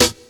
14__verb.wav